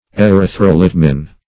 Search Result for " erythrolitmin" : The Collaborative International Dictionary of English v.0.48: Erythrolitmin \E*ryth`ro*lit"min\, n. [Gr.
erythrolitmin.mp3